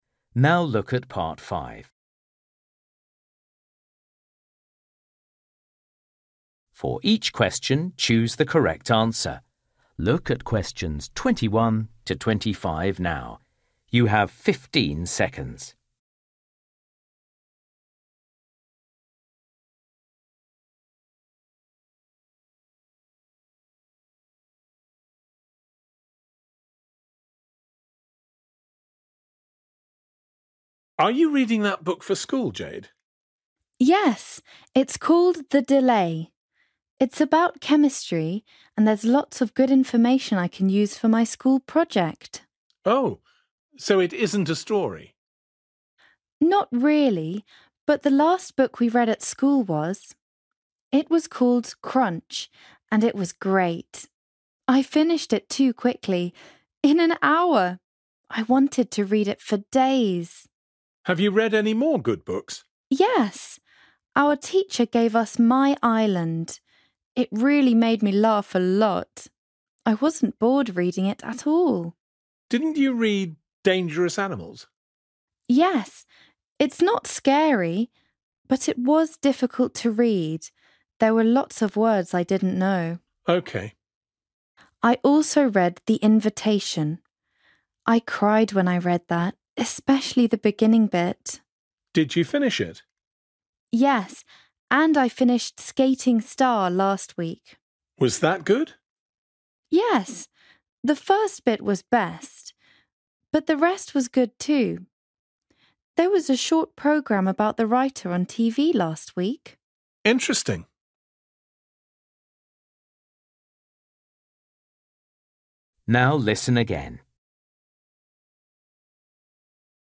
You will hear a girl talking to her uncle about the books she has read at school.